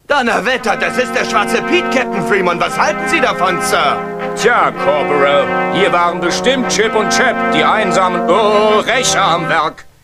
Hier sind ein paar Samples aus frühen SuperRTL-Aufzeichnungen.